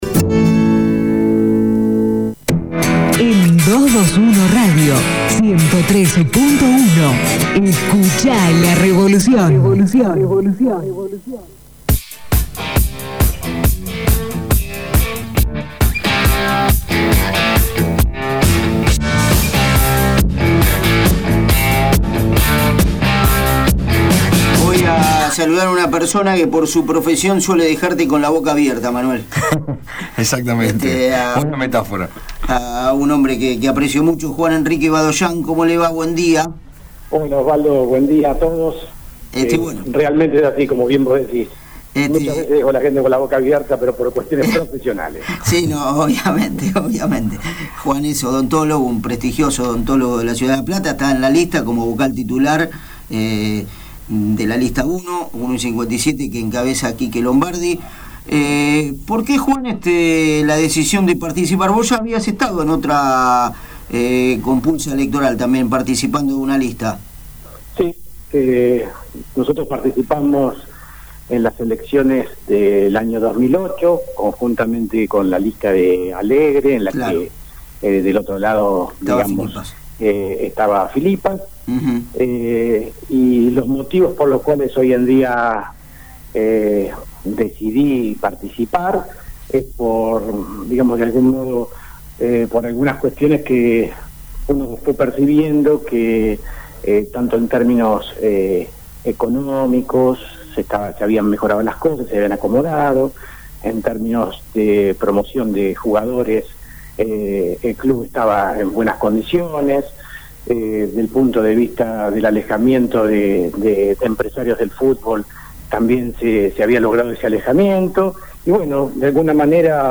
una entrevista